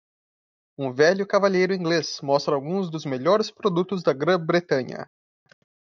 Pronounced as (IPA)
/ka.vaˈʎe(j).ɾu/